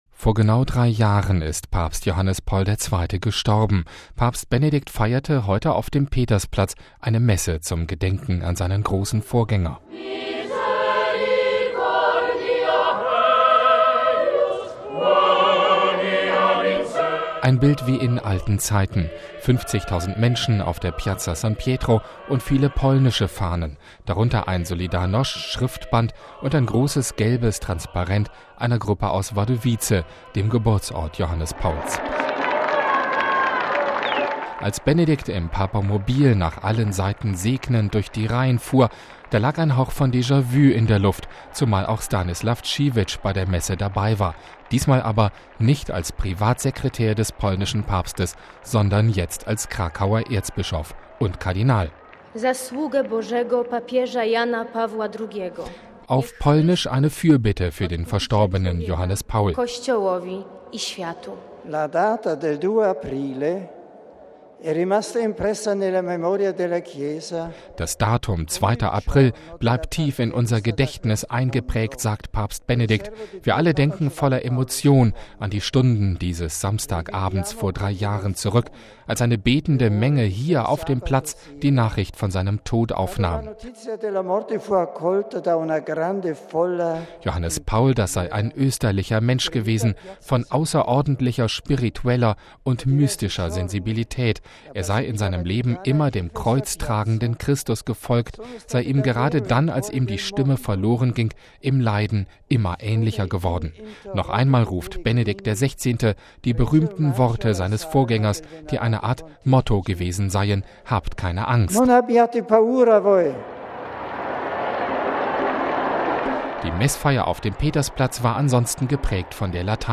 Papst Benedikt feierte auf dem Petersplatz eine Messe zum Gedenken an seinen großen Vorgänger.
Die Messfeier auf dem Petersplatz war geprägt von der lateinischen Sprache.
In einem kurzen Grußwort auf deutsch meinte Benedikt am Schluss der Messfeier: